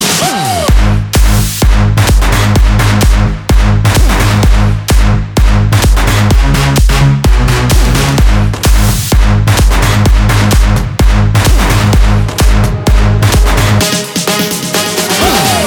• Качество: 321, Stereo
громкие
мощные
progressive house
electro house
Энергичный клубняк